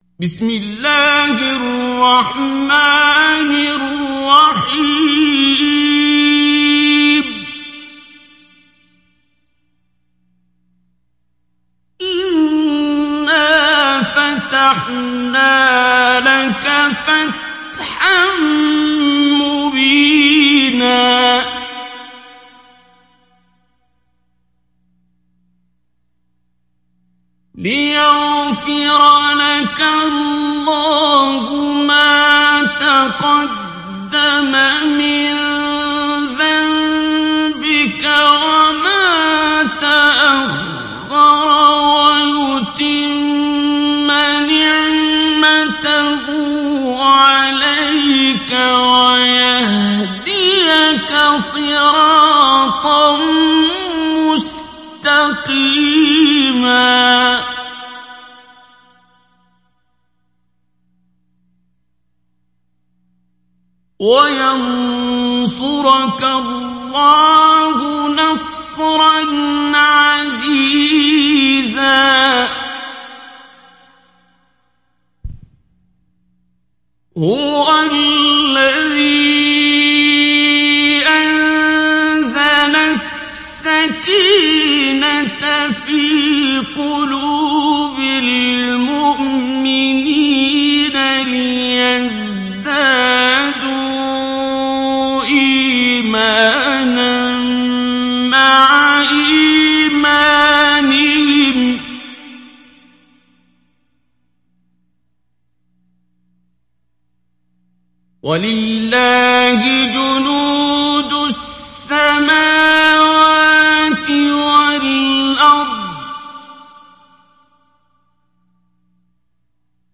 دانلود سوره الفتح عبد الباسط عبد الصمد مجود